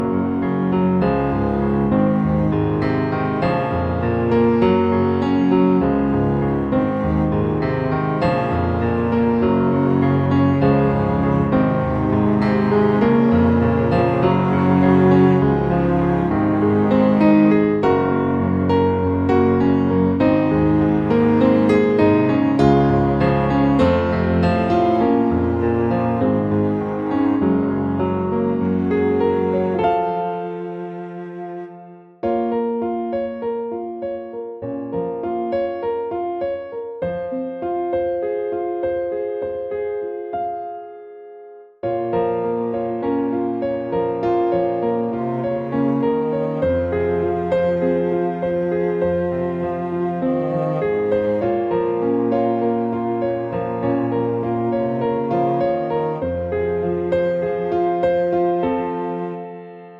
Down 4 Semitones